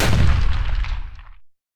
Slam.wav